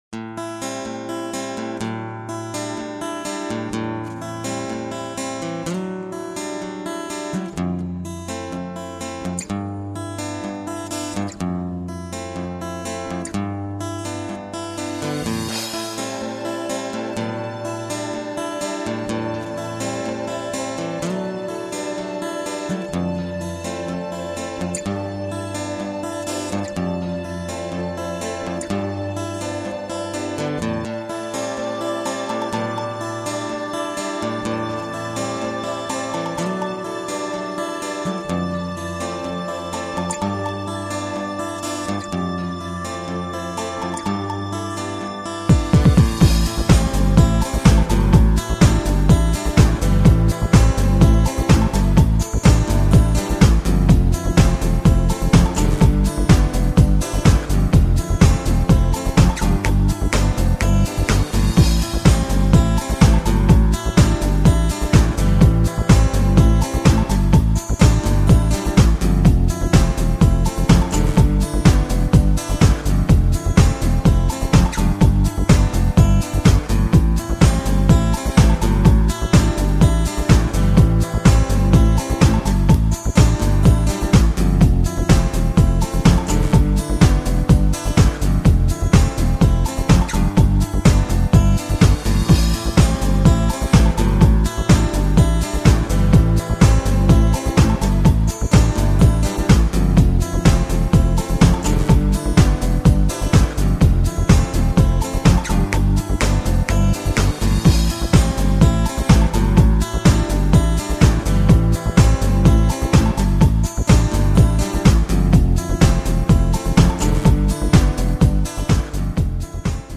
2-ambient.mp3